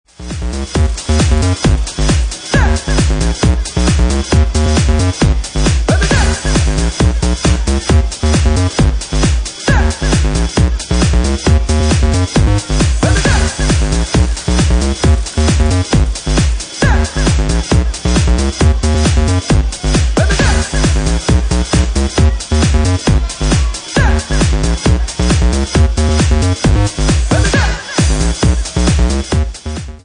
Genre:Bassline House
Bassline House at 67 bpm